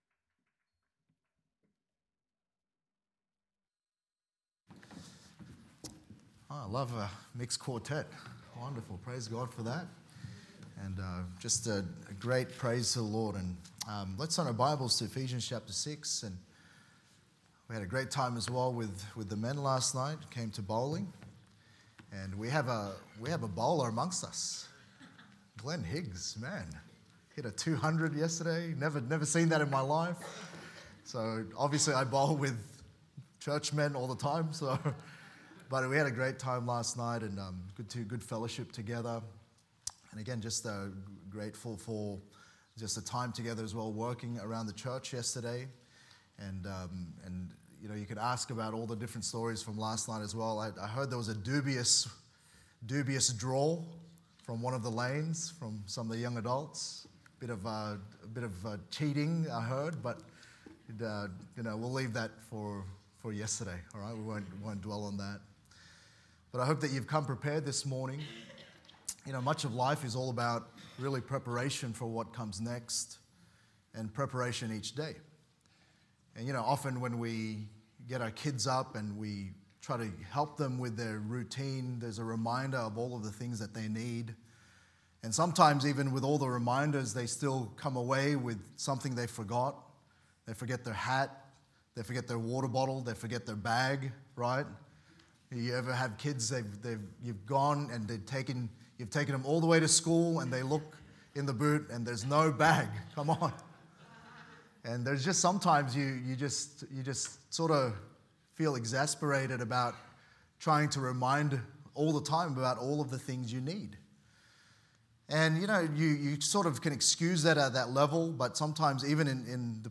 Sermons | Good Shepherd Baptist Church